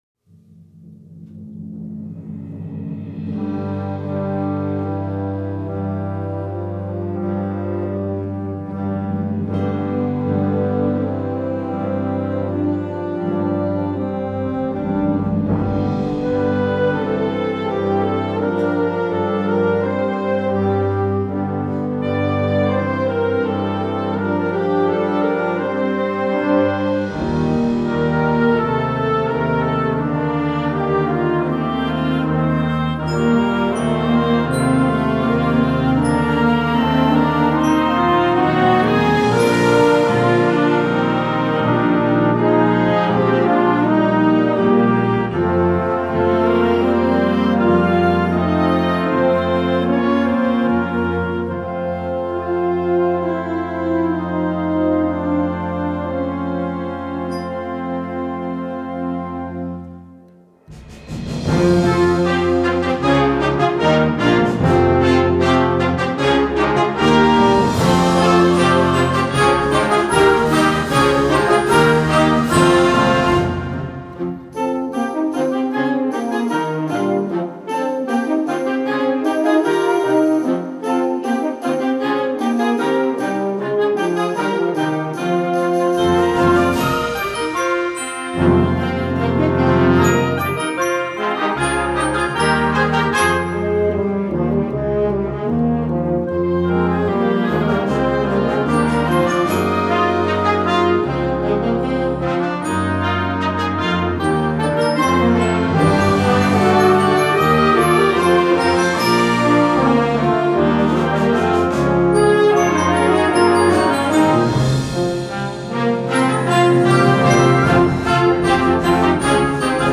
Instrumental Concert Band Concert/Contest
Concert Band